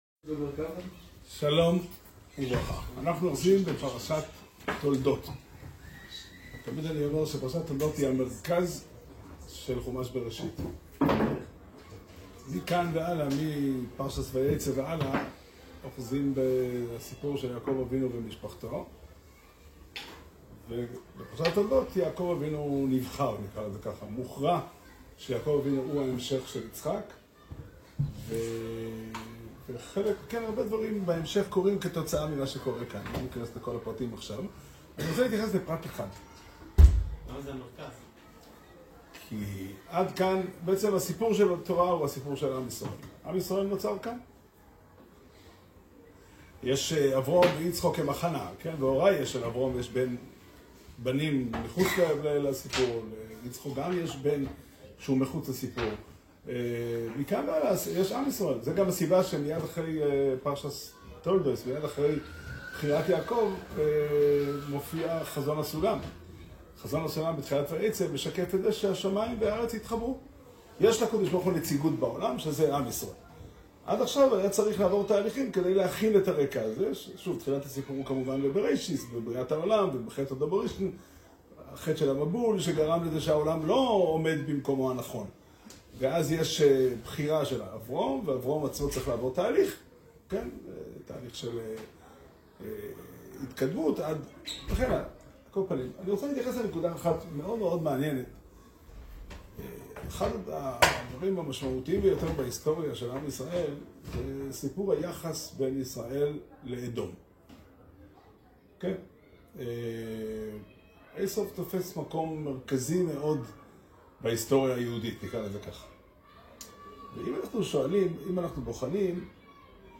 שיעור שנמסר בבית המדרש פתחי עולם בתאריך כ"ד חשוון תשפ"ה